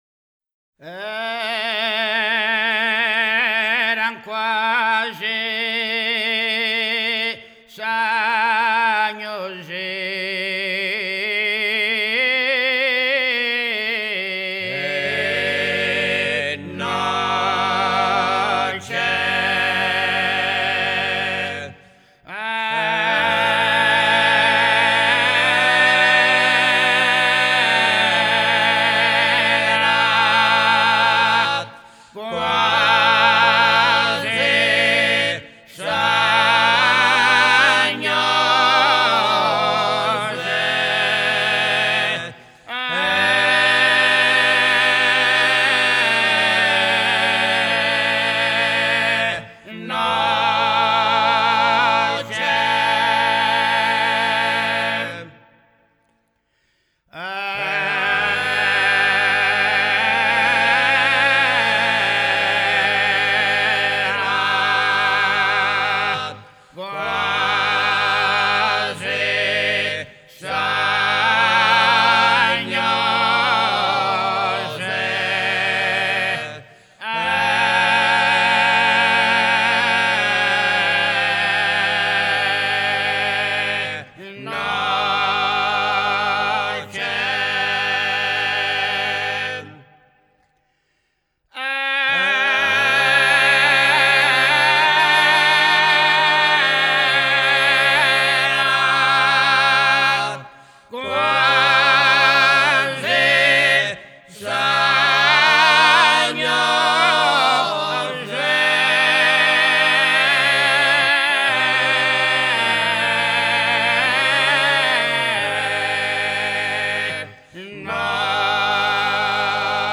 – Castelsardo (Anglona)
Coro della Confraternita di Santa Croce
L’Eram quasi, il cui testo è tratto dall’innografia latina, viene eseguito a quattro voci durante la celebrazione della Santa Messa al momento dell’offertorio.
“Eram quasi”, whose text is taken from Latin hymnography, is performed by four voices during the offertory of the Holy Mass.